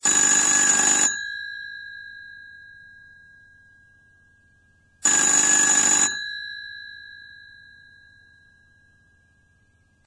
altesTelefon.mp3